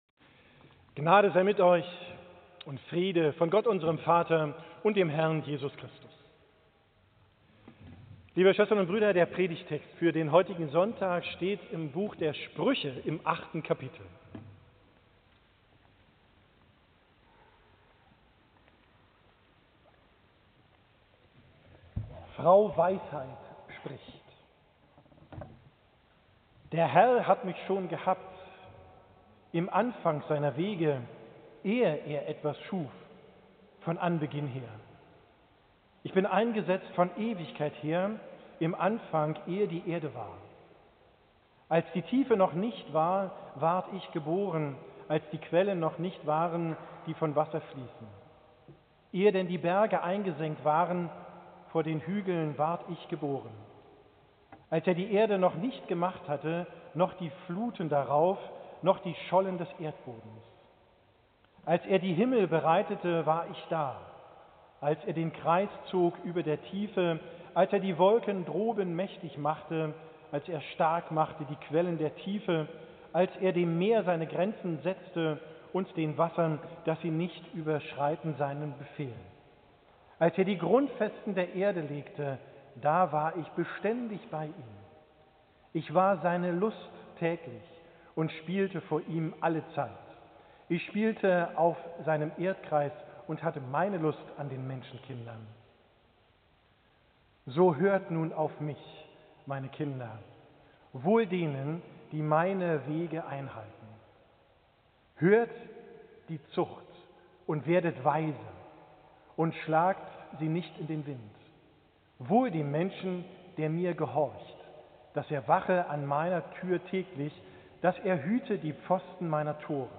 Predigt vom Sonntag Jubilate, 11.